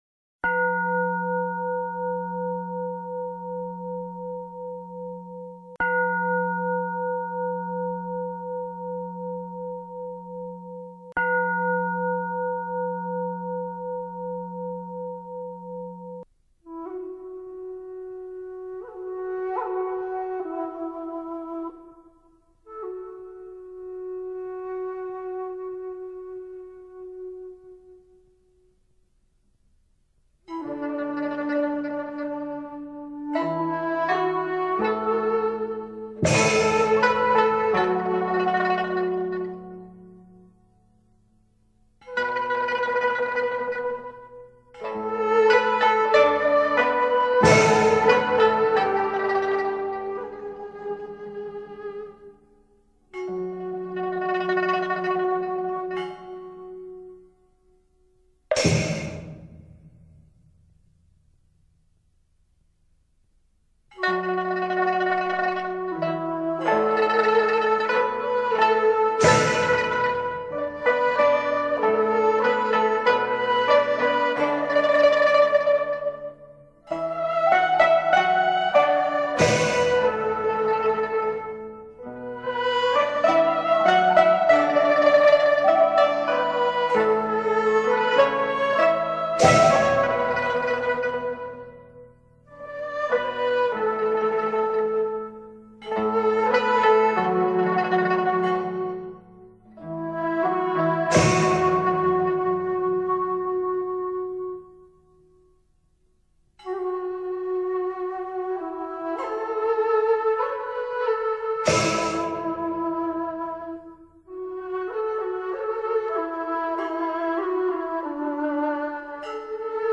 太空漫步配乐.mp3